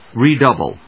音節re・dou・ble 発音記号・読み方
/rìːdˈʌbl(米国英語), ri:ˈdʌbʌl(英国英語)/